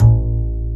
Index of /90_sSampleCDs/Roland L-CDX-01/BS _Jazz Bass/BS _Acoustic Bs